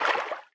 Sfx_creature_symbiote_swim_slow_02.ogg